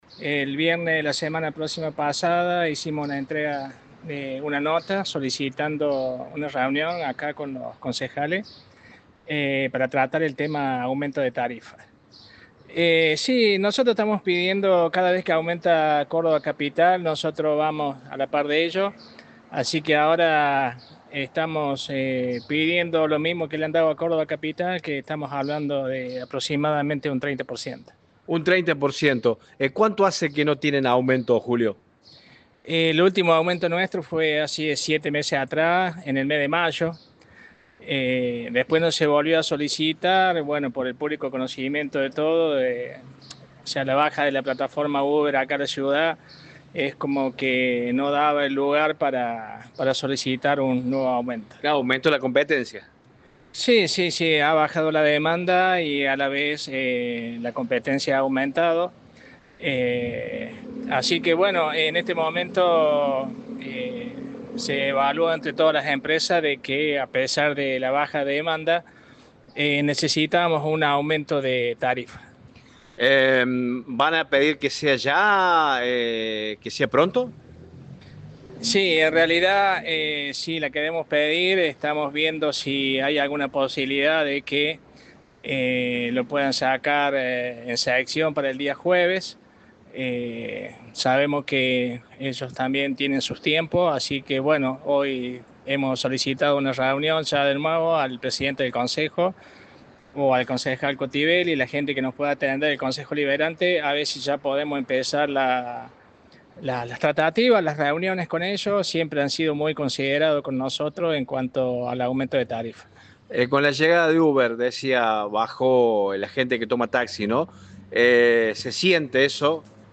en diálogo con Radio Show